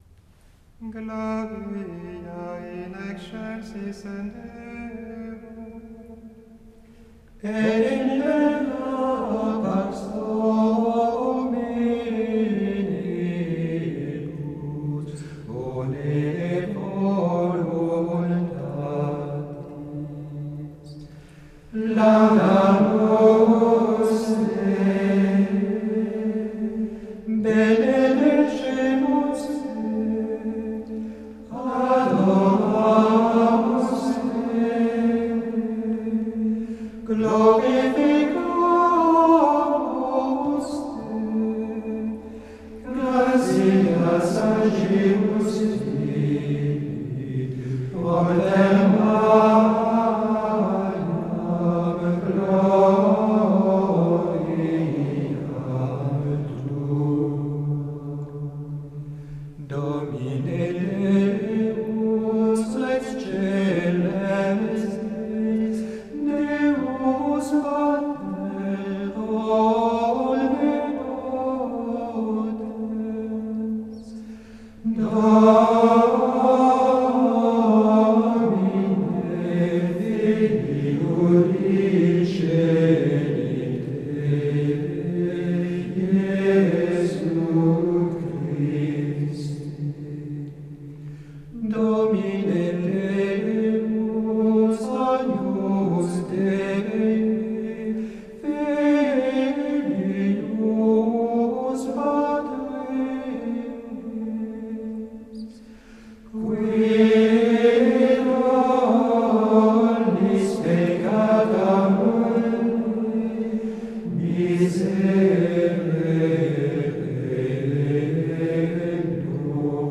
Il emprunte sa mélodie au 1er mode. Il est léger, mais il laisse place aussi à une certaine exubérance, remarquable par son alternance entre les passages syllabiques ou quasi-syllabiques, assez propres à ce répertoire des Glória, et des parties neumées, peut-être plus présentes que dans beaucoup d’autres Glória. Il y a donc beaucoup de joie, et même d’exultation dans cette pièce.
Et le Amen conclusif est large et très chaud.
Voilà un beau Glória, solennel et chaleureux, alternant très heureusement les passages enthousiastes et les formules très intimes.